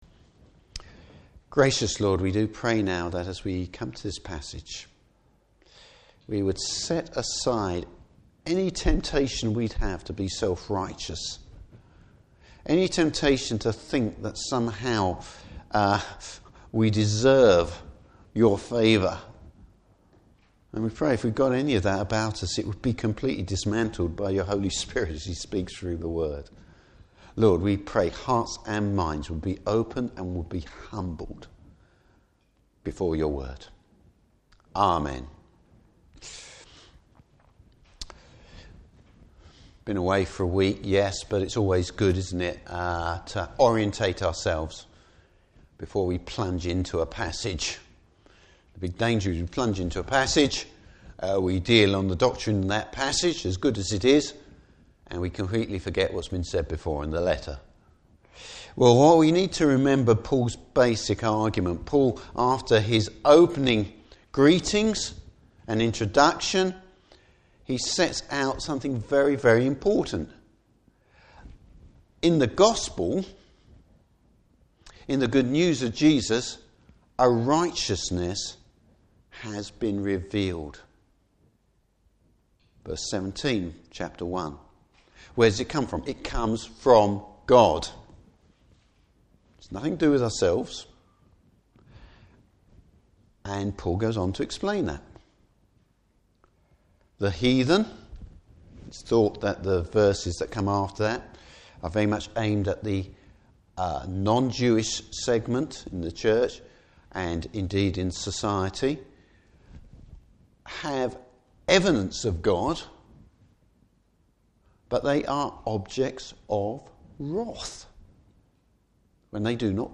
Service Type: Morning Service The rightness of God’s anger at sin.